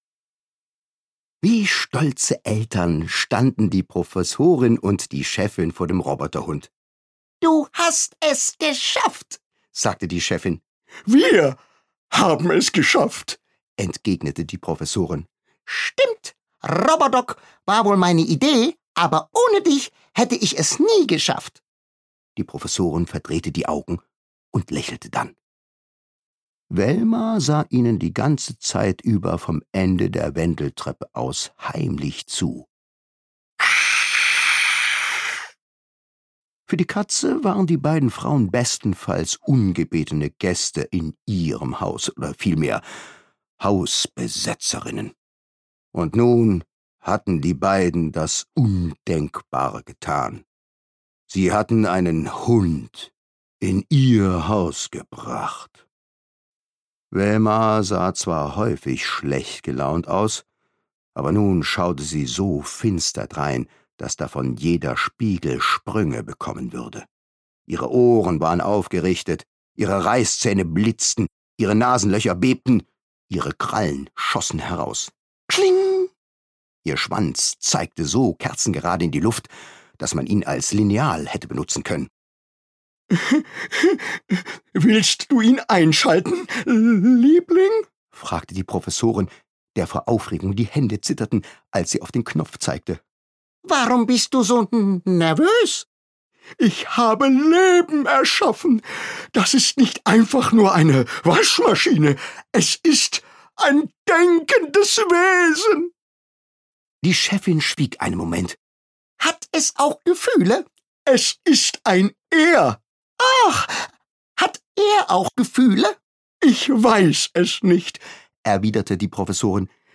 Interpret: Thomas Nicolai
Er bellt, maunzt, schlurzt und hurzt, dass die Fetzen und Lefzen fliegen – Eine wahre Hörfreude!
Da hat man tatsächlich das Gefühl, man hört ein Comic an. Und das es nur ein Sprecher ist, ist auch schwer zu glauben, ist aber so.